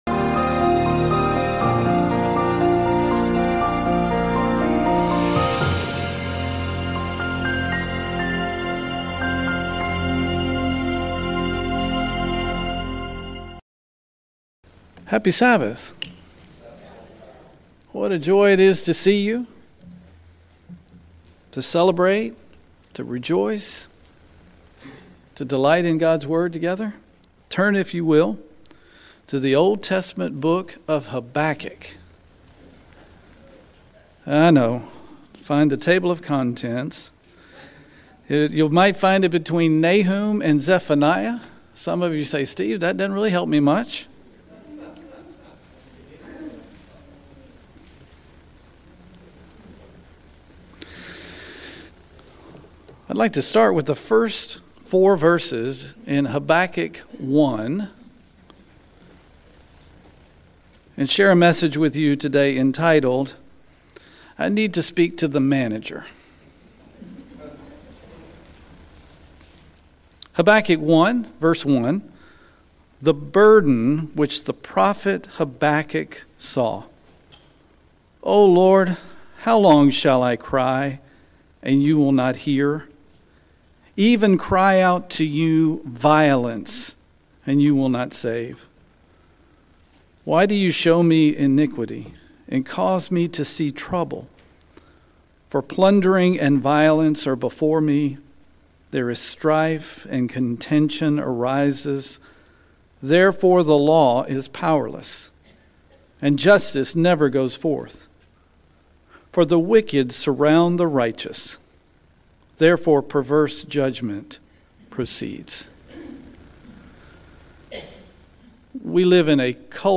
We live in a culture of complaining, and it is easy to get wrapped up in it. This sermon explores the book of Habakkuk for a model for complaining TO GOD rather than ABOUT GOD, and how this model may draw us into a more meaningful relationship WITH GOD.
Given in Houston, TX